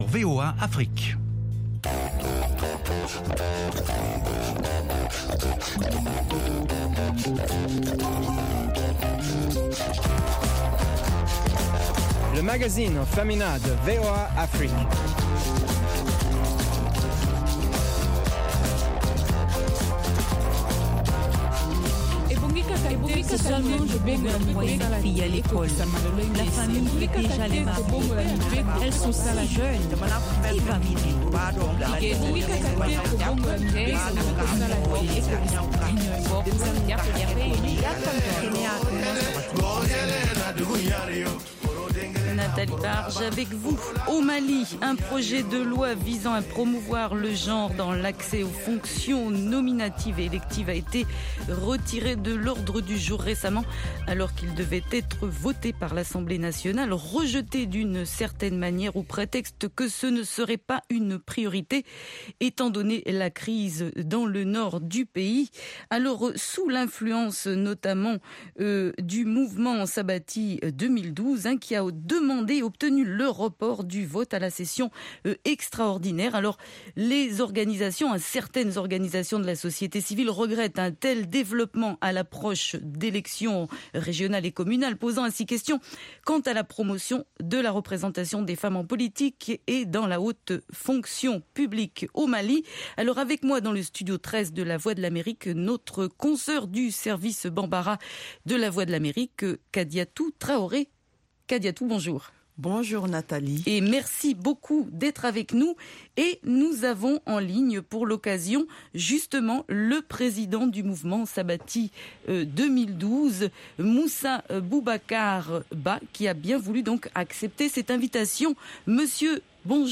LMF présente également des reportages exclusifs de nos correspondants sur le continent.